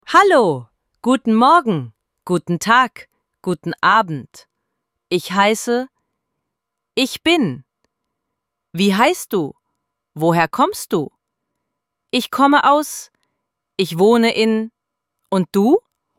ElevenLabs_Text_to_Speech_audio-32.mp3